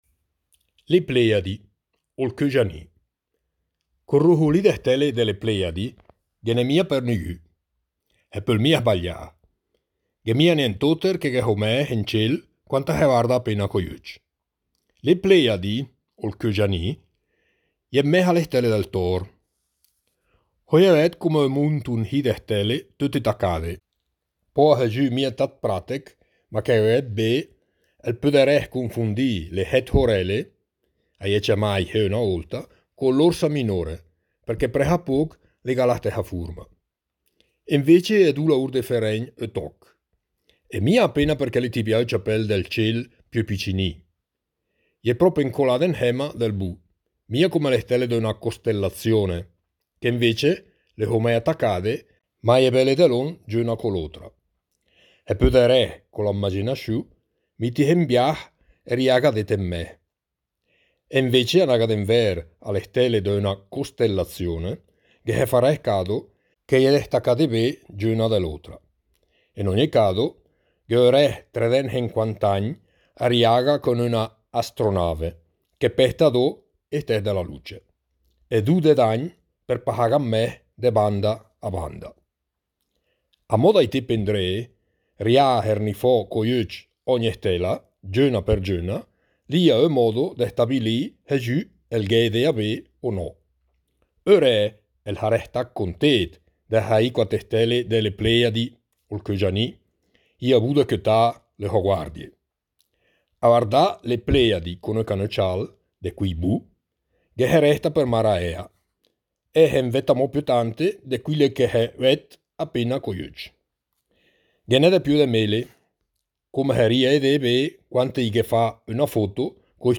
22 – Le Plejadi, Ol Cögianì (dialetto lumezzanese)
22_Pleiadi_dialetto_lumezzanese.mp3